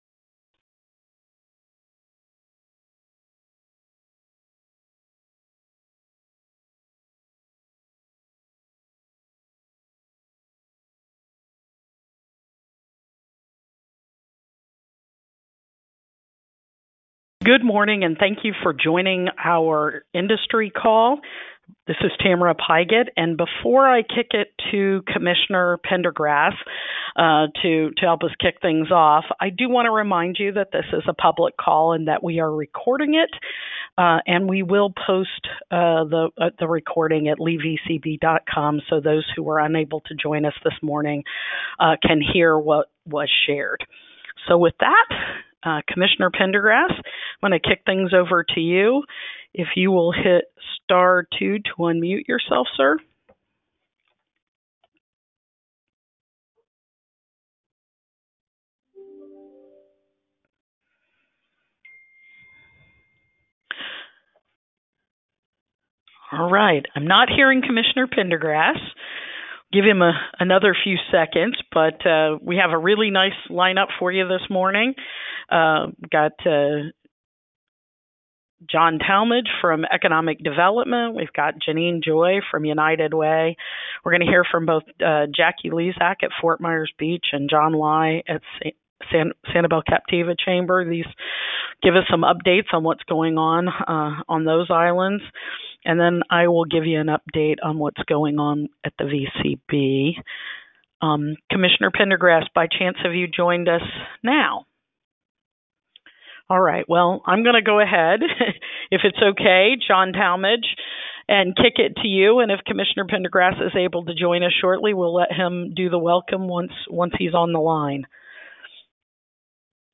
Monthly Industry Call | Visit Fort Myers | SW Florida